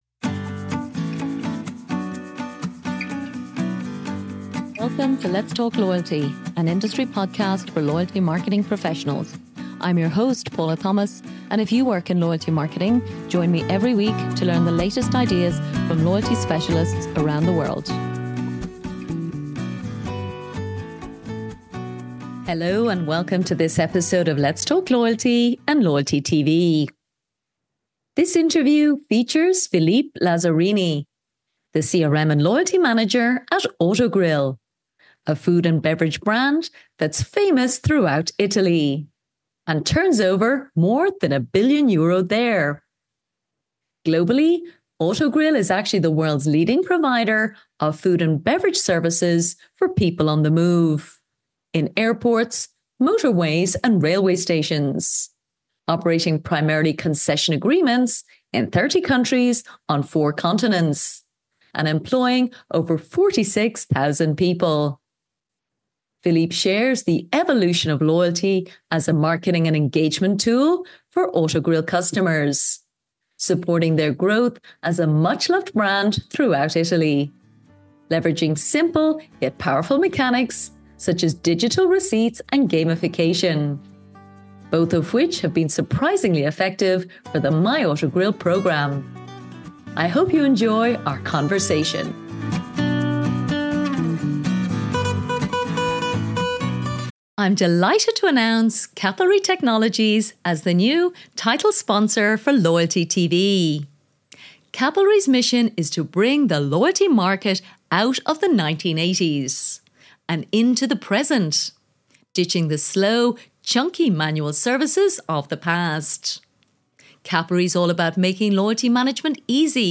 Today’s interview